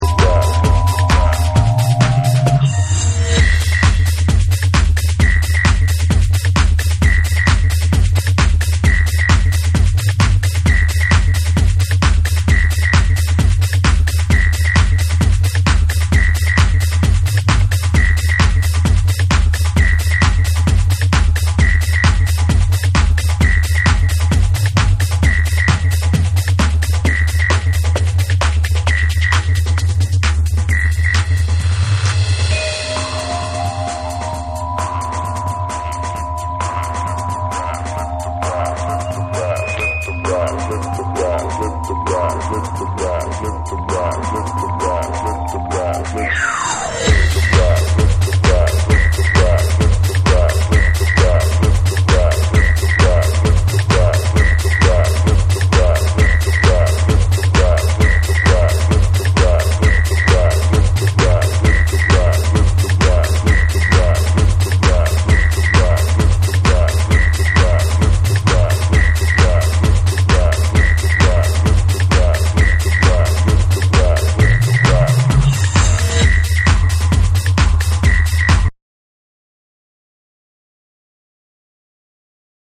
BREAKBEATS / TECHNO & HOUSE